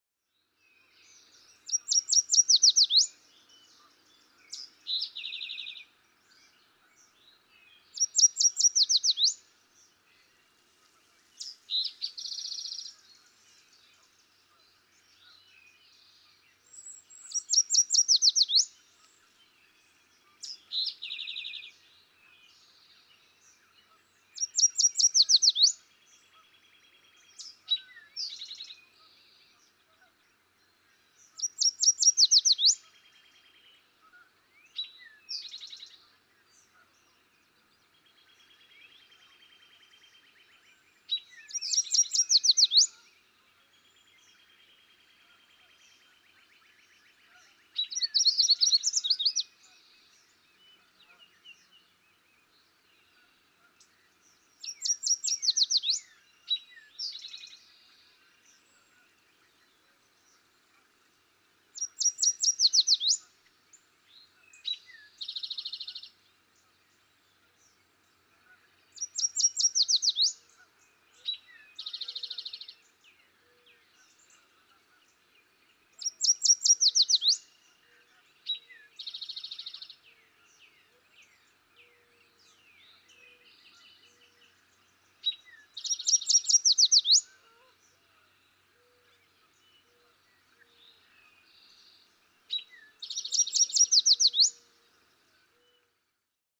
Yellow warbler
Daytime singing, with one particular song in his repertoire repeated over and over. With eastern towhee in the background.
Parker River National Wildlife Refuge, Newburyport, Massachusetts.
489_Yellow_Warbler.mp3